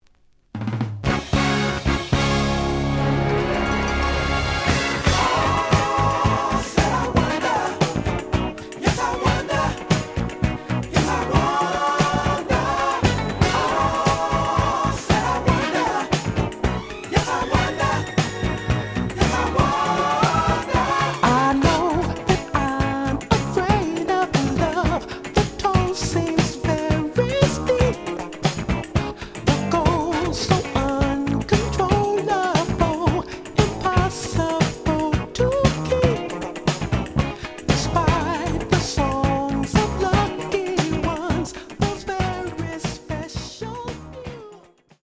The audio sample is recorded from the actual item.
●Genre: Soul, 80's / 90's Soul
Slight edge warp.